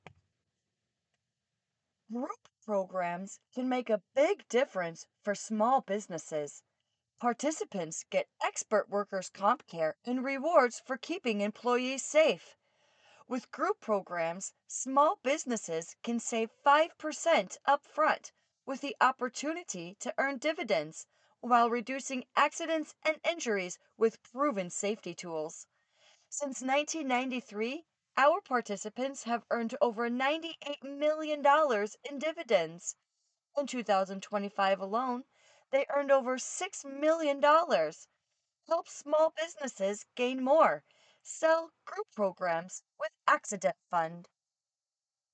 Professional-grade recording equipment and acoustically treated space